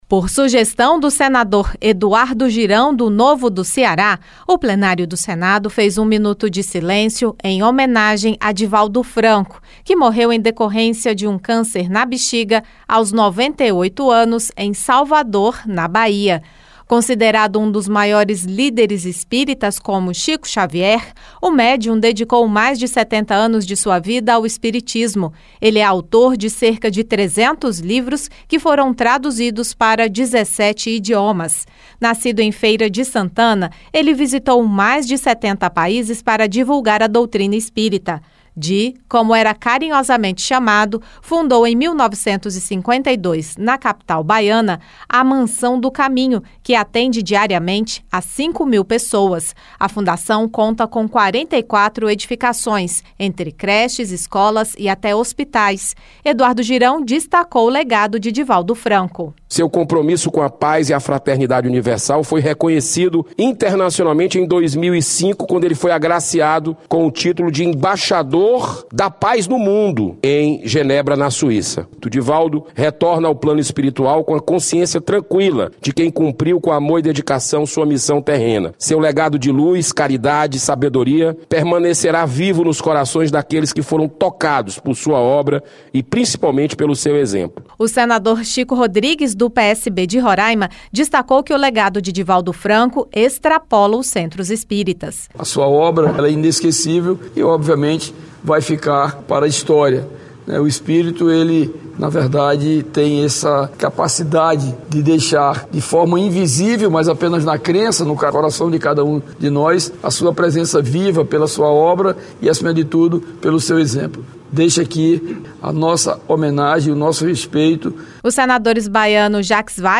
O Plenário do Senado fez um minuto de silêncio nesta quarta-feira (14) em homenagem a Divaldo Franco, que morreu em decorrência de um câncer na bexiga aos 98 anos em Salvador.